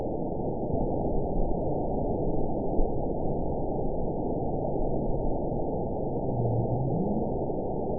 event 921629 date 12/12/24 time 22:02:04 GMT (4 months, 4 weeks ago) score 9.24 location TSS-AB02 detected by nrw target species NRW annotations +NRW Spectrogram: Frequency (kHz) vs. Time (s) audio not available .wav